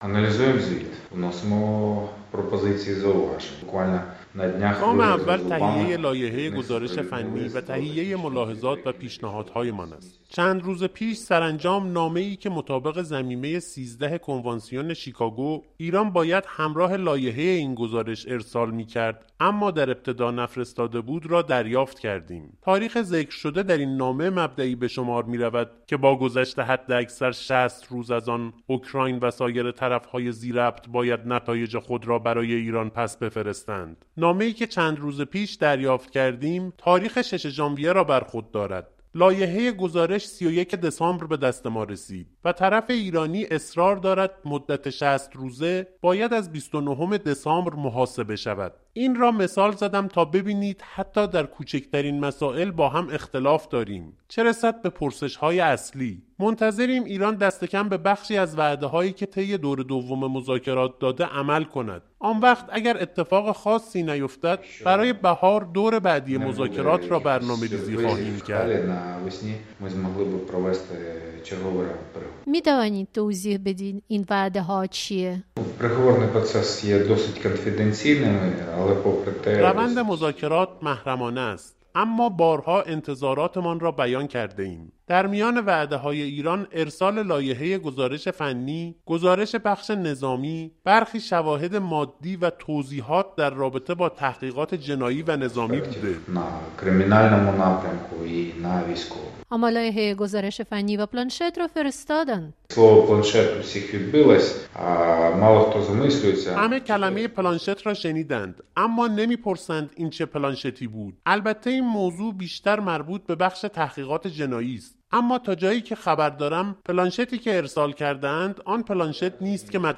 یک‌ماه پس از نخستین سالگرد سرنگونی هواپیمای اوکراینی؛ گفت‌وگو با یوگنی ینین، سرپرست هیئت مذاکره‌کنندگان اوکراینی در پرونده پرواز ۷۵۲